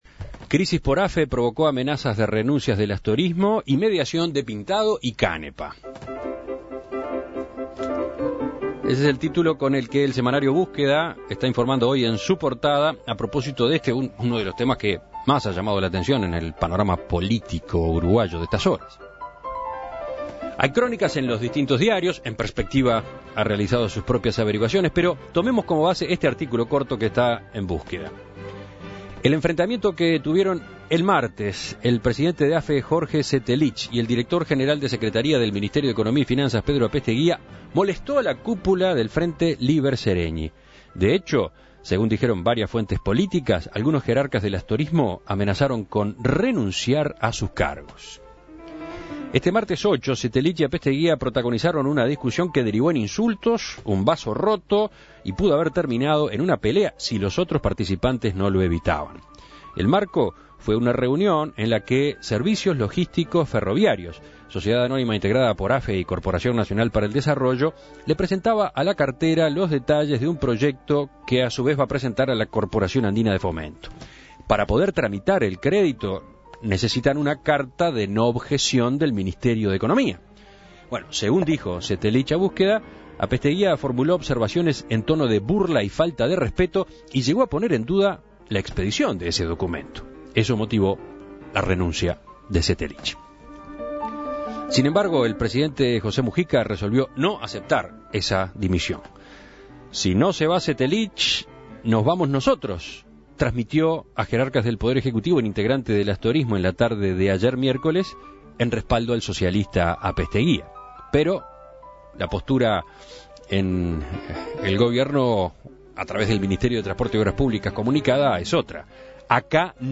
El presidente de la Administración de Ferrocarriles del Estado (AFE), Jorge Setelich, presentó ayer su renuncia al directorio de la empresa, que no fue aceptada por el presidente Mujica. En diálogo con En Perspectiva, Setelich profundizó en las razones que lo llevaron a esta decisión.
Entrevistas